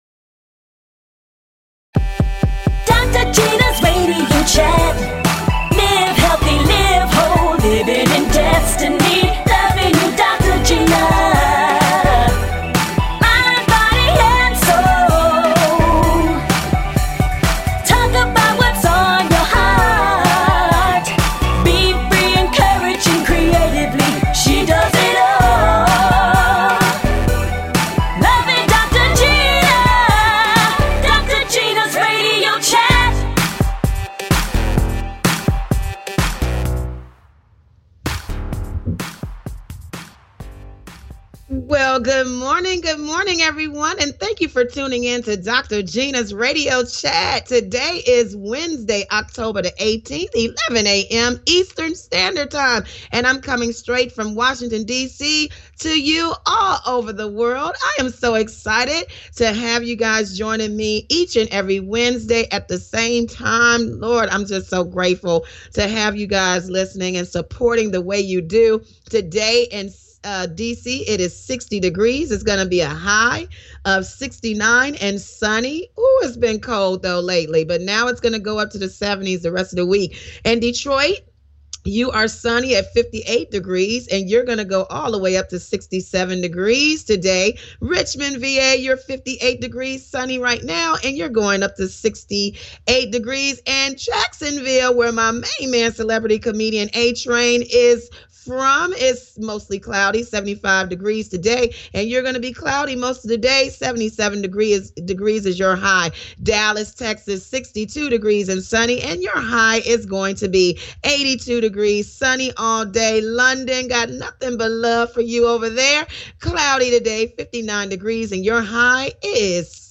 Talk Show
Fun! Exciting! And full of laughter!